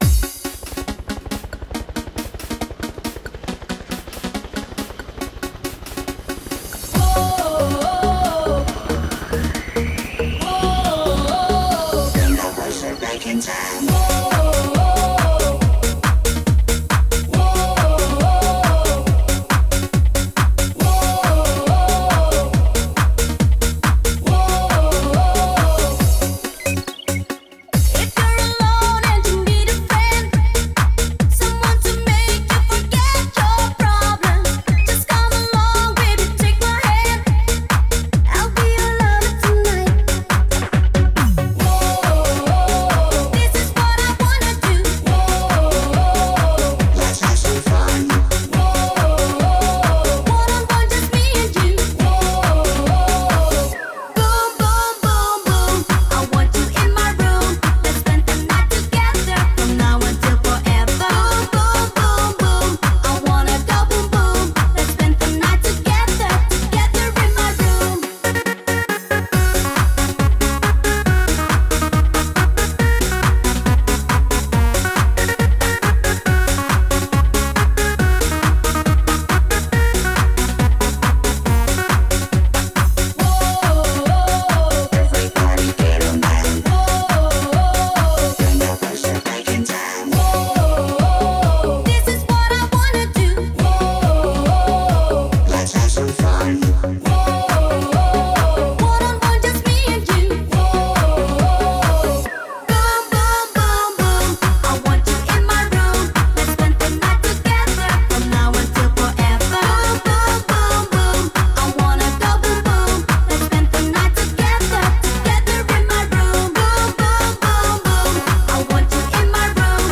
BPM138
Audio QualityLine Out